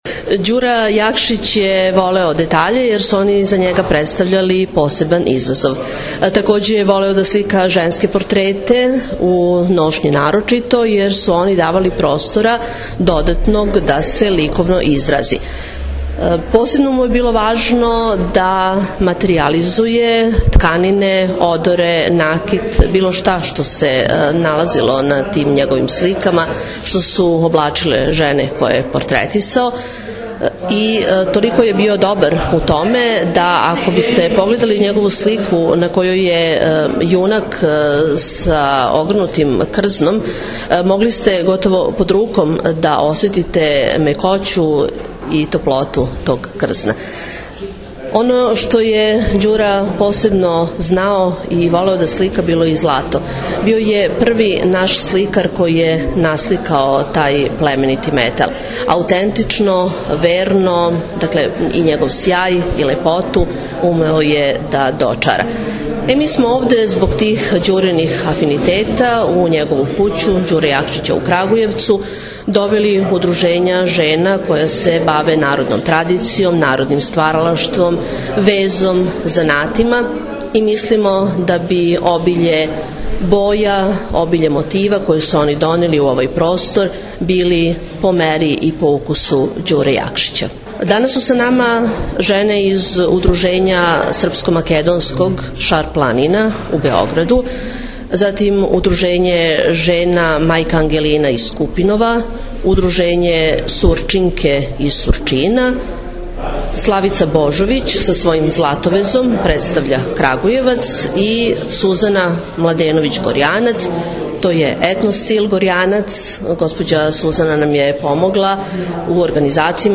У кући Ђуре Јакшића у суботу, 26. септембра је одржан „Ђурин етно дан”. То је манифестација која је окупила пет удружења која се баве очувањем народне традиције, рукотворина и свим оним што чини идентитет једног народа.